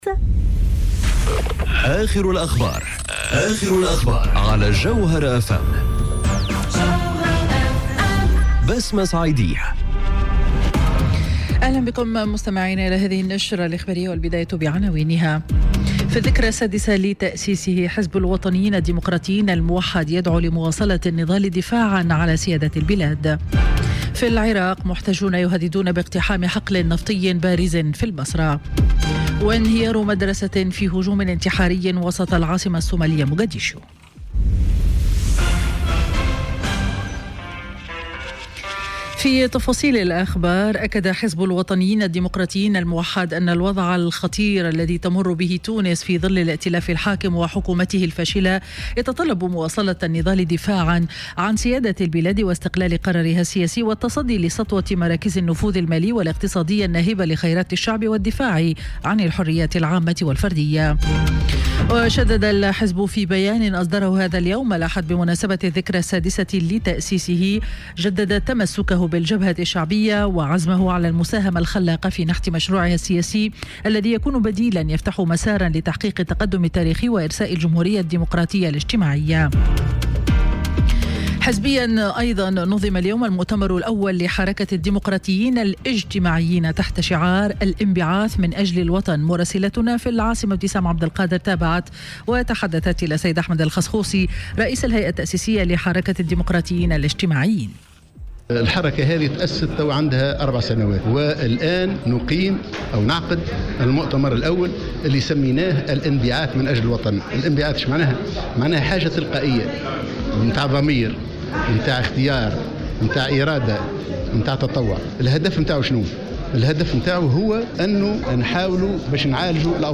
نشرة أخبار منتصف النهار ليوم الأحد 02 سبتمبر 2018